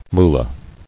[moo·la]